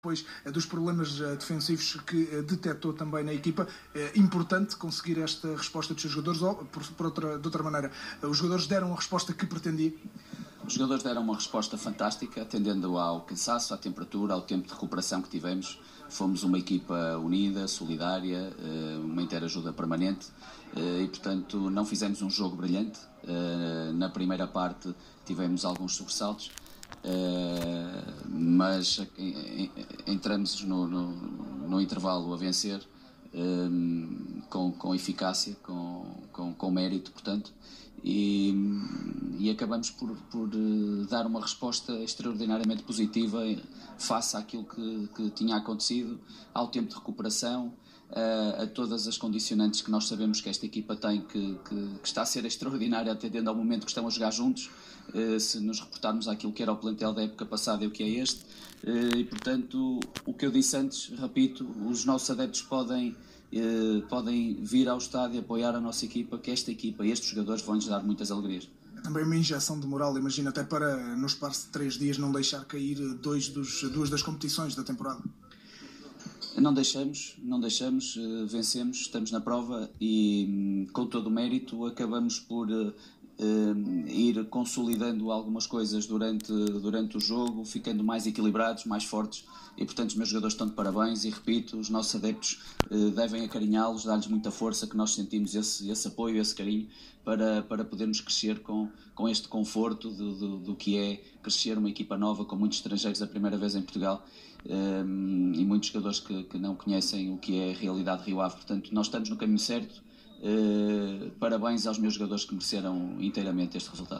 na zona de entrevistas rápidas da Sport TV